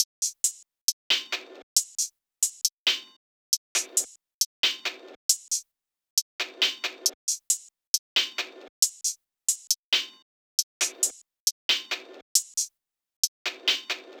drumloop 14 (136 bpm).wav